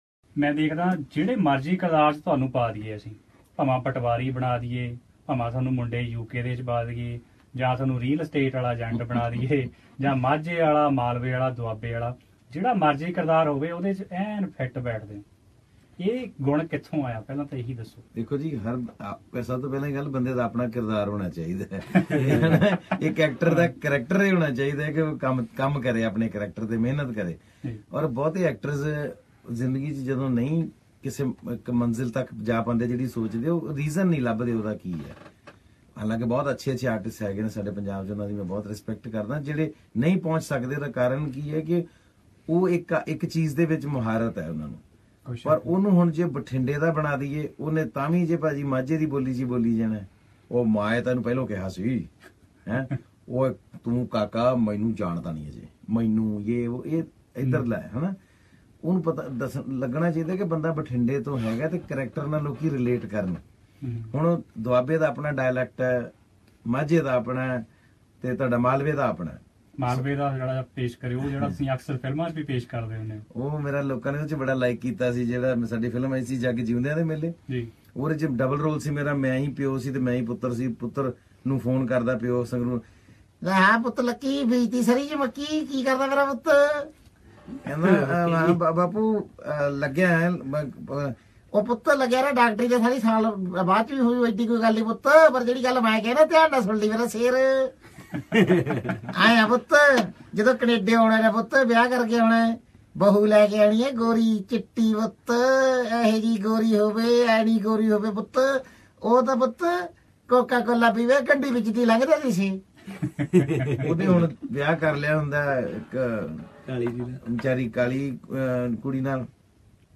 Punjabi has various dialects, which have similar words spoken in various accents with small variability. At times a person can be identified based on his origin from Punjab's Majha, Duaba, or Majha region (or Puaa'dh).
at SBS Melbourne studios Source